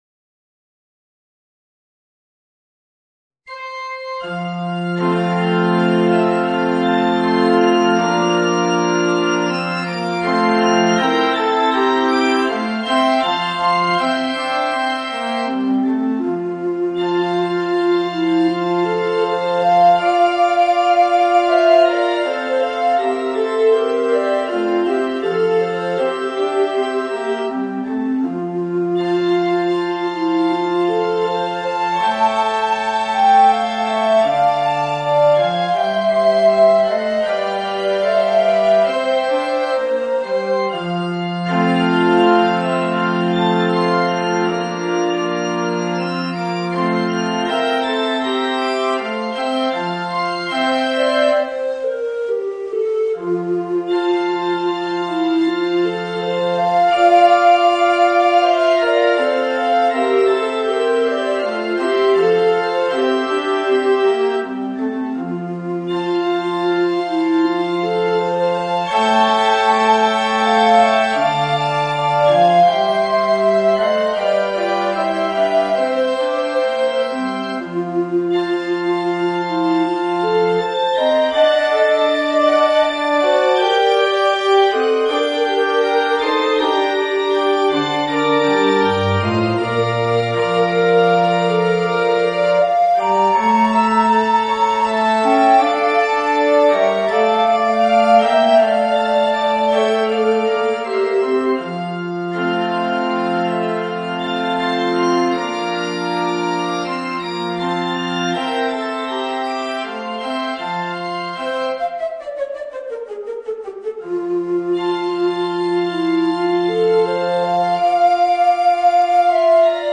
Voicing: Tenor Recorder and Piano